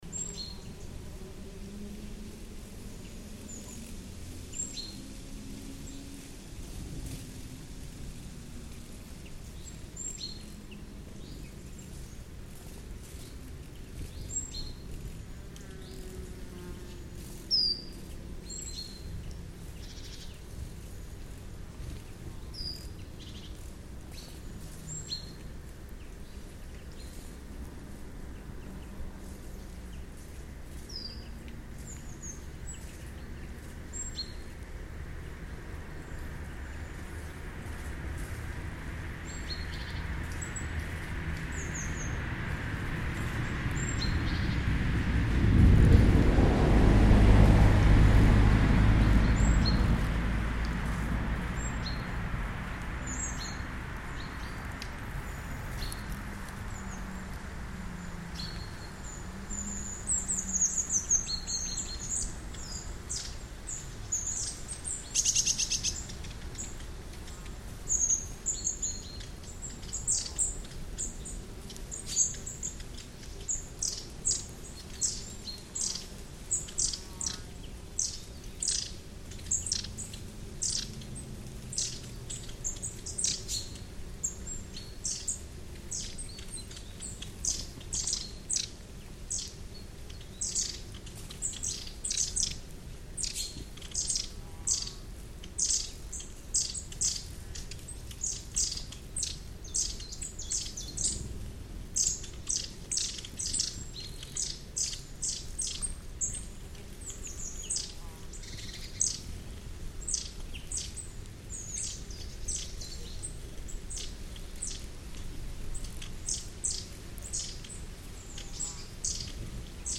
Behind the cross, a short distance from the road, the old burial plot has turned into a wild pond covered with duckweed. You can hear a flock of tits flying over the water surface, dragonflies interested in the microphones, and at one point, a raven appears on a tall tree.